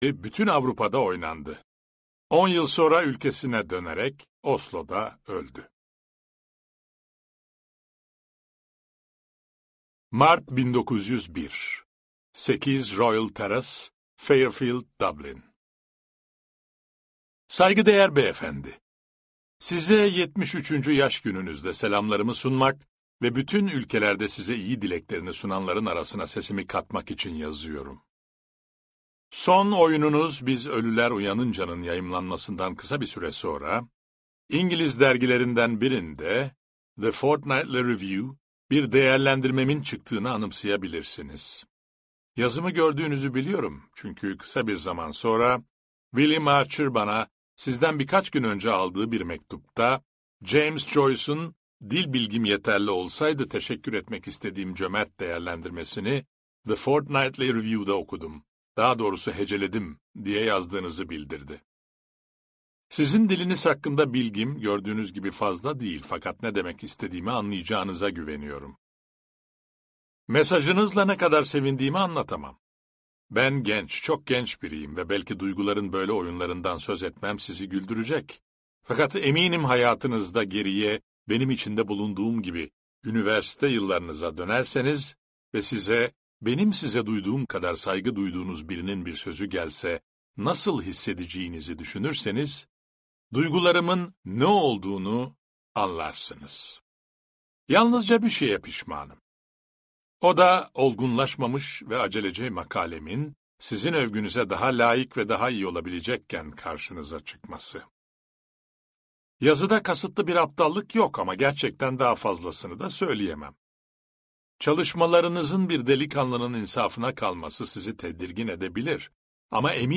Sanatçının Mektupları - Seslenen Kitap